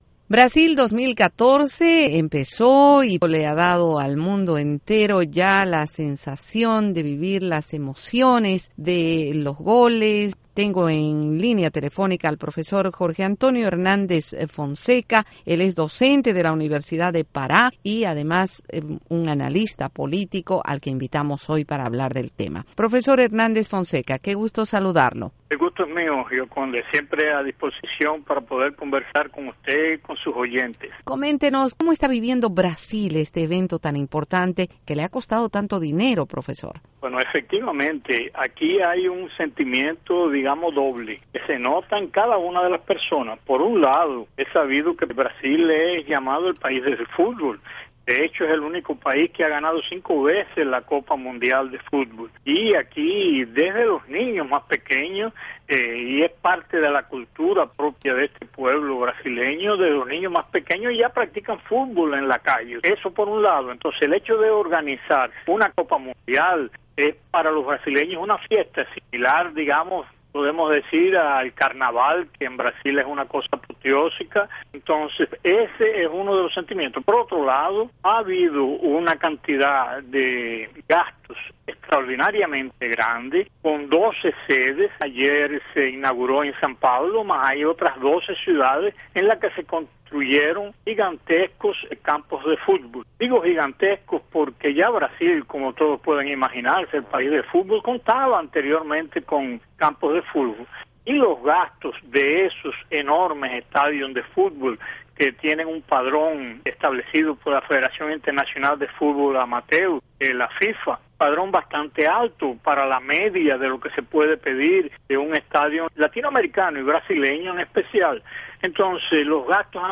Entrevista al analista político brasileño